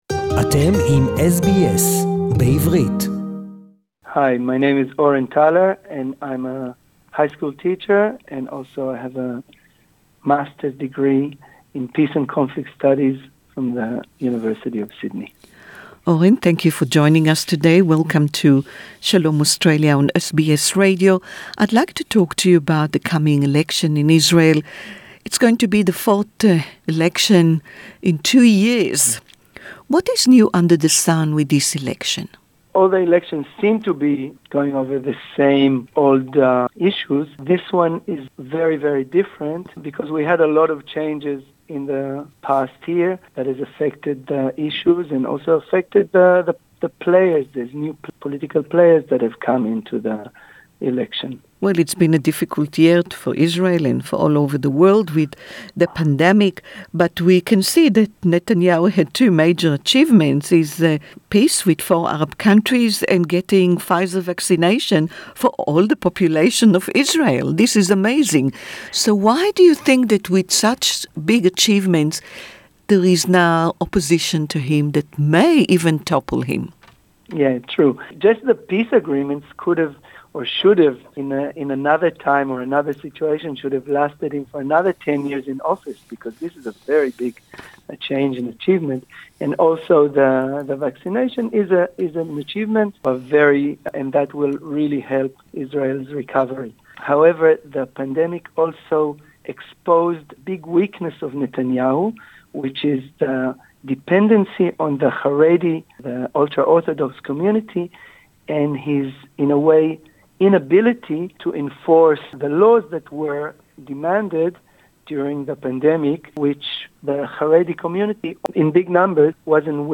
English interview